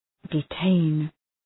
Shkrimi fonetik {dı’teın}